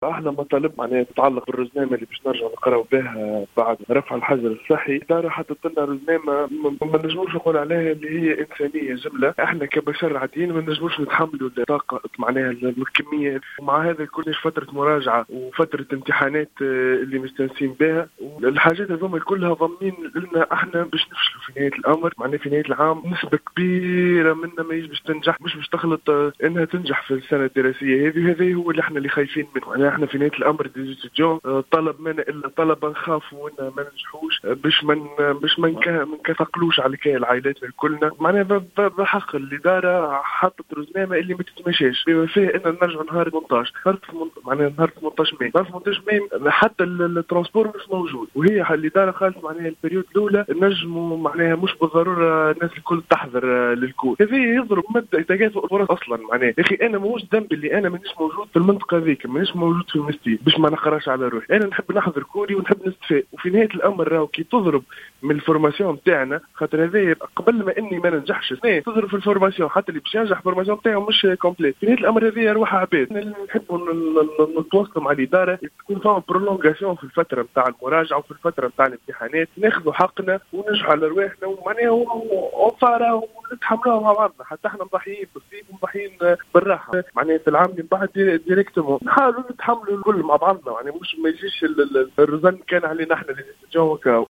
عبّرعدد من الطلبة بكلية الطب بالمنستير عن رفضهم لرزنامة العودة الجامعية وتواريخ عطلة المراجعة والإمتحانات التي وضعتها الكلية بعد توقف الدروس بسبب وباء كورونا المستجد وفق ما صرّح به أحد الطلبة للجوهرة أف أم.
أحد الطلبة